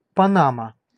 Ääntäminen
Etsitylle sanalle löytyi useampi kirjoitusasu: Panama panama Synonyymit boater Ääntäminen US US UK : IPA : /ˈpæn.ə.mɑː/ US : IPA : /ˈpæn.ə.mɑː/ Lyhenteet ja supistumat (laki) Pan.